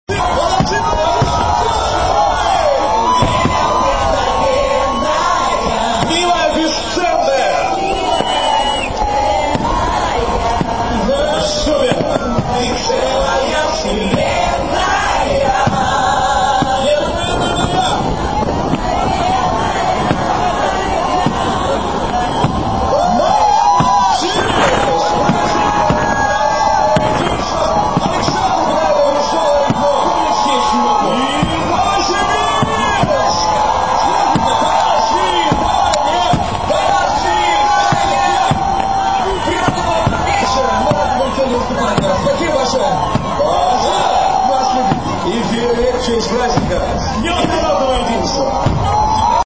НОВОСИБИРСК, ПЛОЩАДЬ ЛЕНИНА, 4 НОЯБРЯ 2005 ГОДА